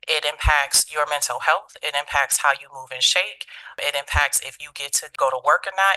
Commissioner Monteze Morales pushed for the formal statement.